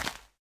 assets / minecraft / sounds / dig / grass3.ogg
grass3.ogg